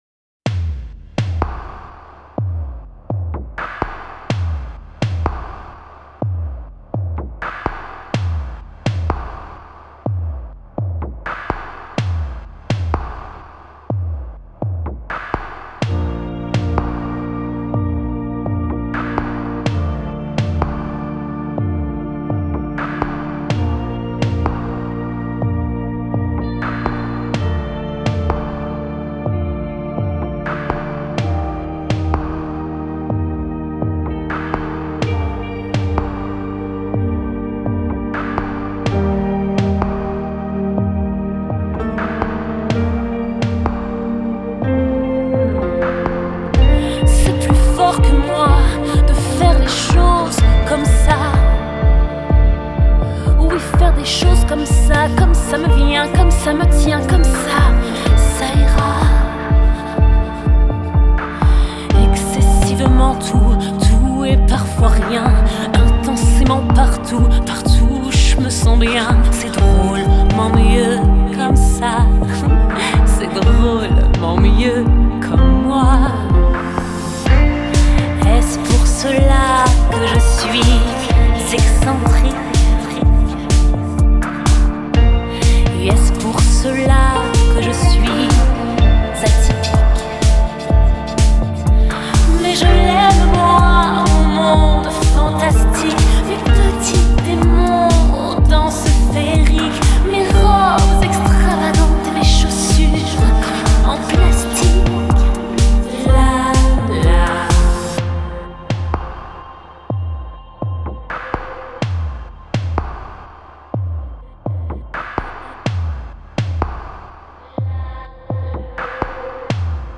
Genre: Pop, Dance, R&B, Soul, French